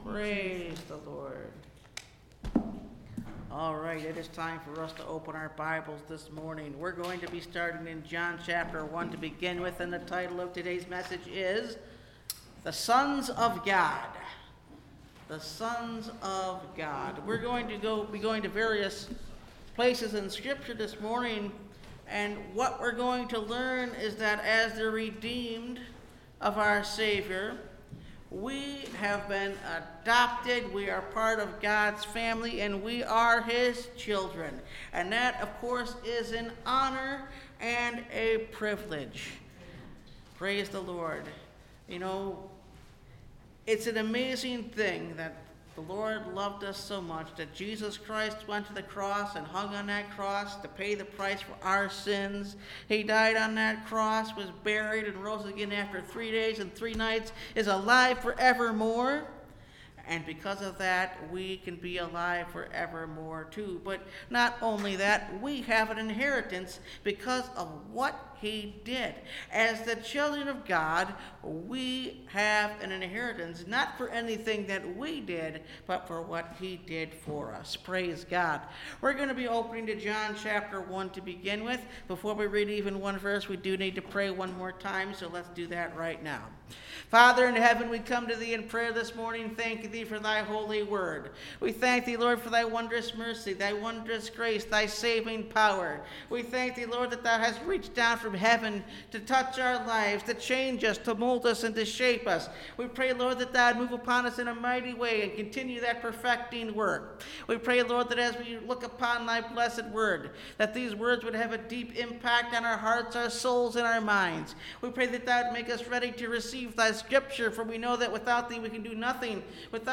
The Sons Of God (Message Audio) – Last Trumpet Ministries – Truth Tabernacle – Sermon Library